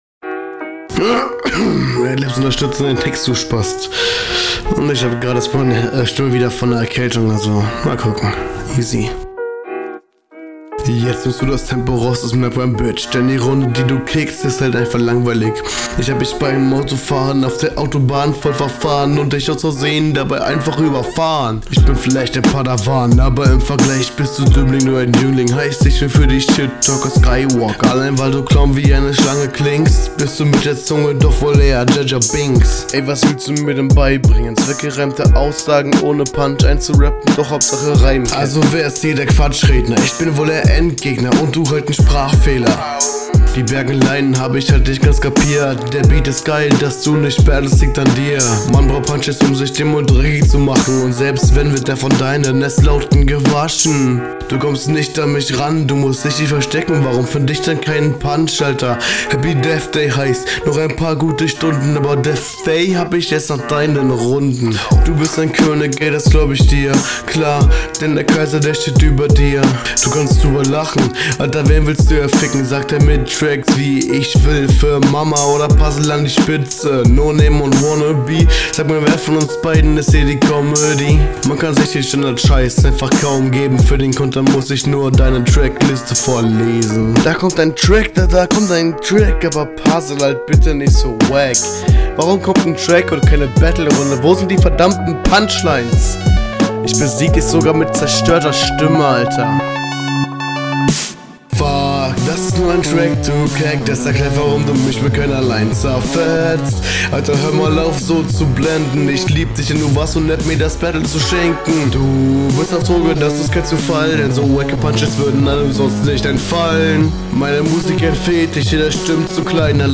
Stimme ist wieder zu laut.
Intro echt laut.